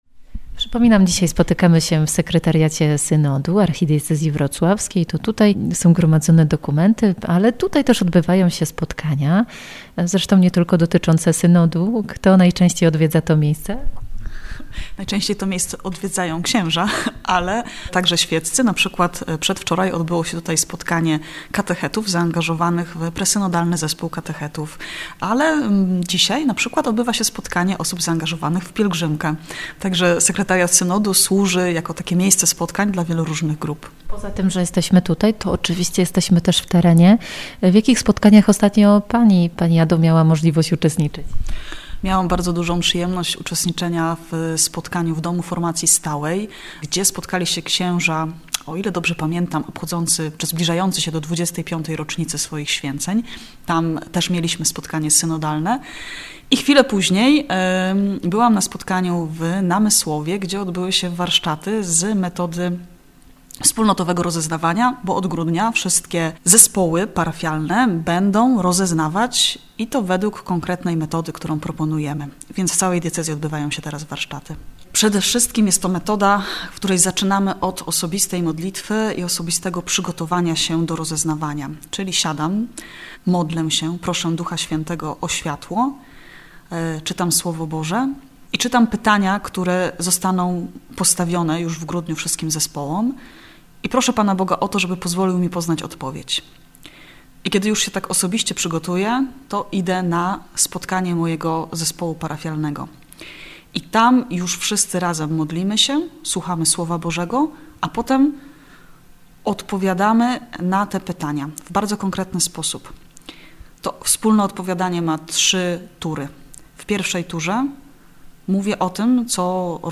Studio Synodalne w Radiu Rodzina w czwartki po godz. 13:10.